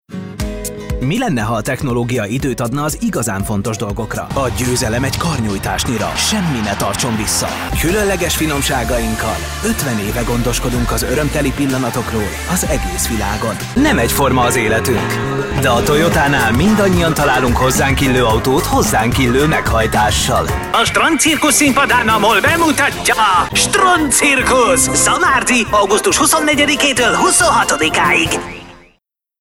Commerciale, Jeune, Enjouée, Amicale, Chaude
Commercial